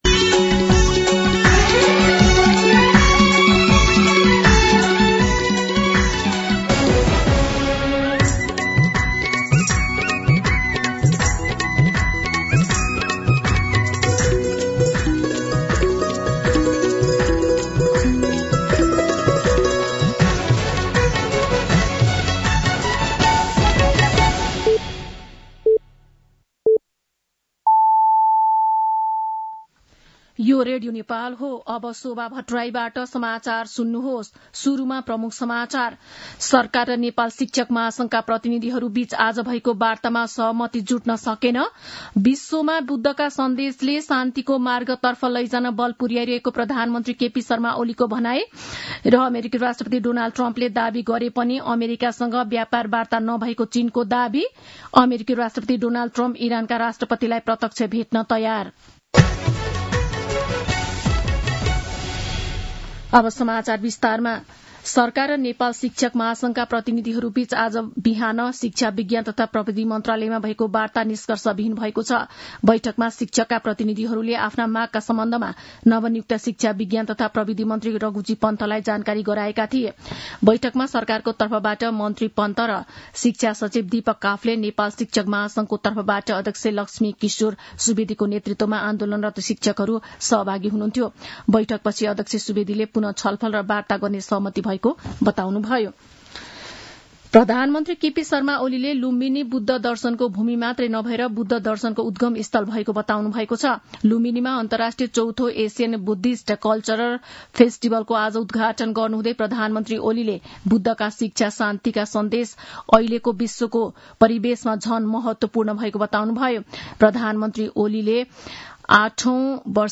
दिउँसो ३ बजेको नेपाली समाचार : १३ वैशाख , २०८२
3pm-News-01-13.mp3